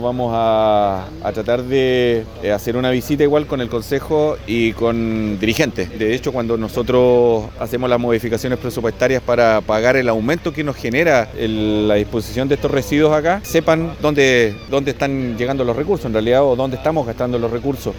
El alcalde de Lago Ranco, Miguel Meza, valoró las mejoras en el vertedero de Morrompulli, anunciando una visita con los concejales para conocer el funcionamiento y los recursos invertidos por la comuna.